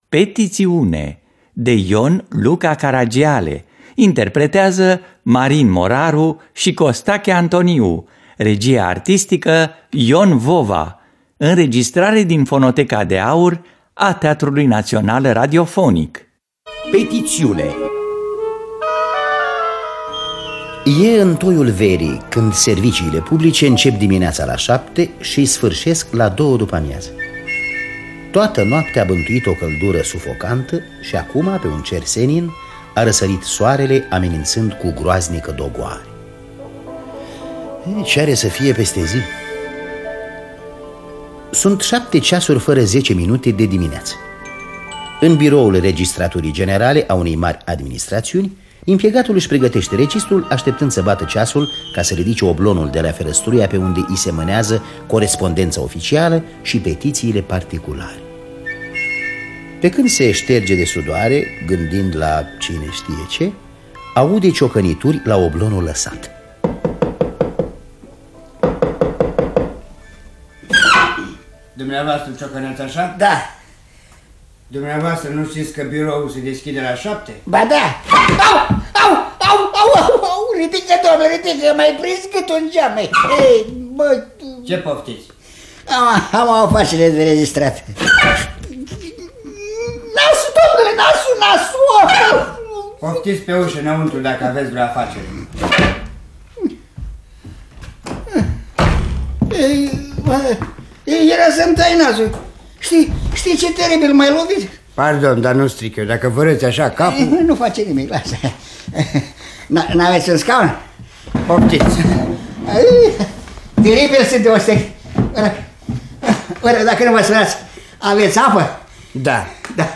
Petițiune de Ion Luca Caragiale – Teatru Radiofonic Online